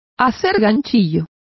Complete with pronunciation of the translation of crocheting.